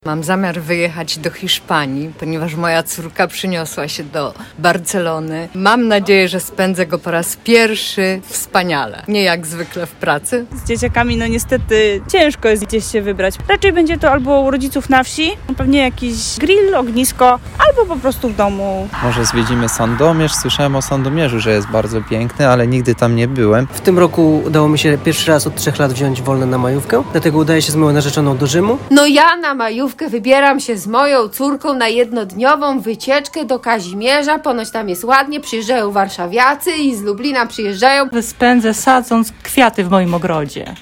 Z tej okazji zapytaliśmy mieszkańców Mielca jak planują spędzić długi weekend.
SONDA-MAJOWKA-SERWIS.mp3